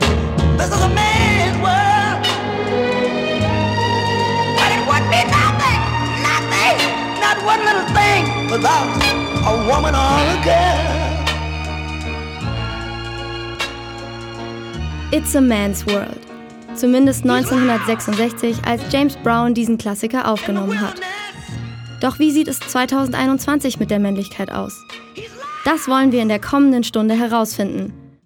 RME-Teaser_430.mp3